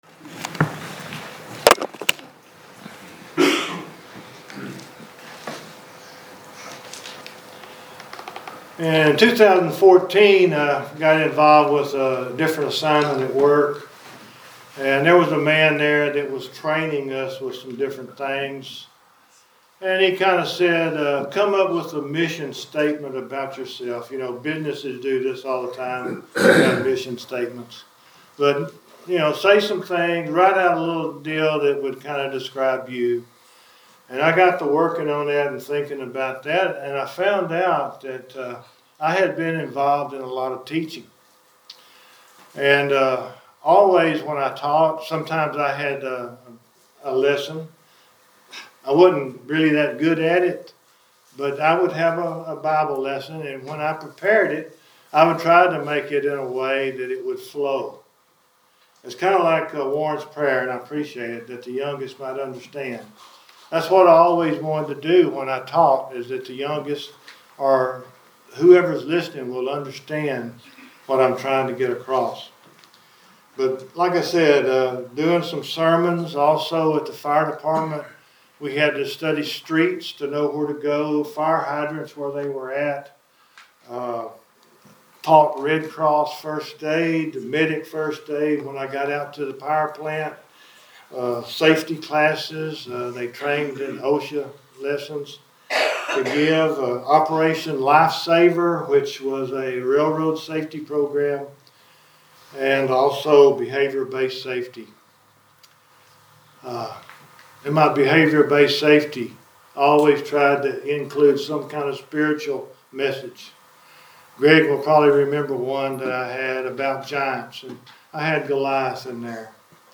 My-Number-1-Sermon.mp3